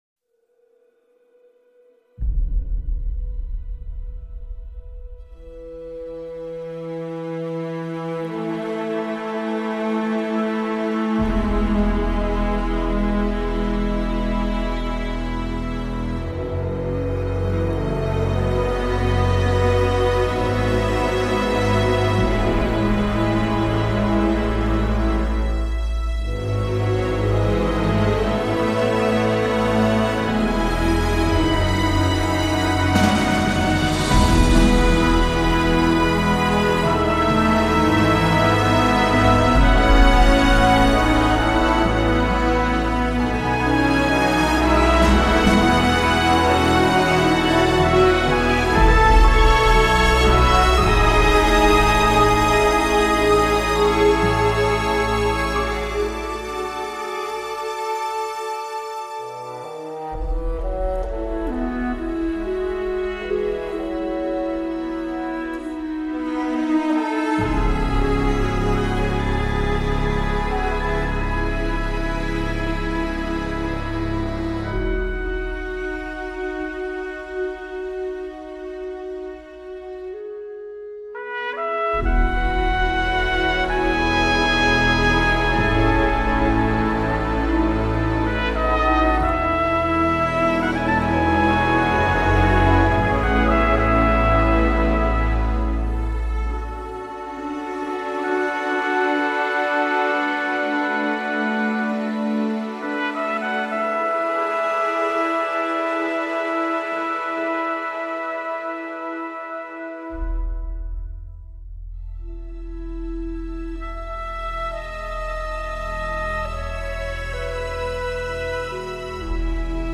vents, piano, cordes, harpe